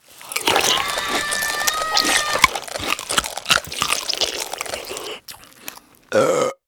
eat.wav